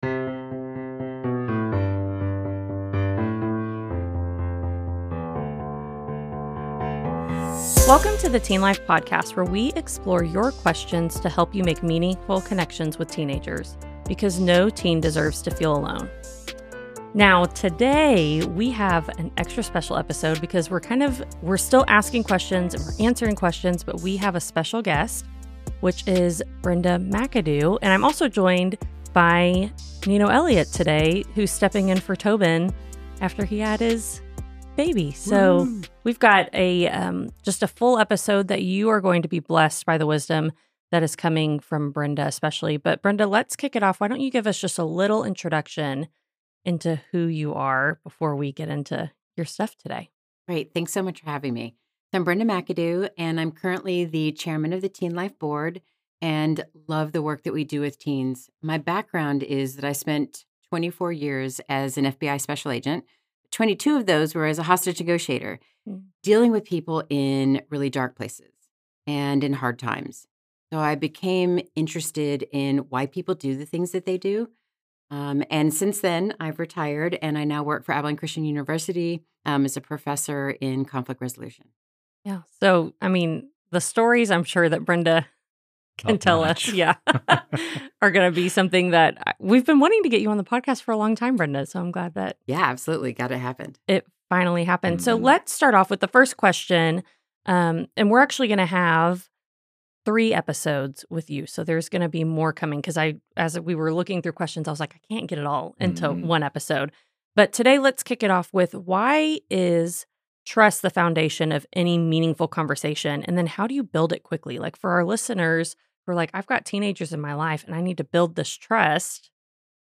Whether you’re a teacher, coach, mentor, or parent, this conversation is packed with insights to help you strengthen your relationships with teens and make a lasting impact.